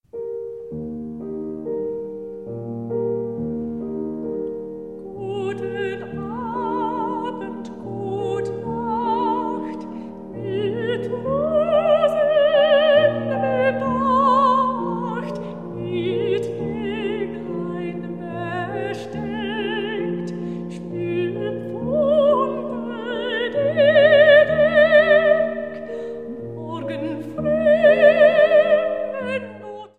Колыбельная